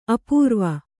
♪ apūrva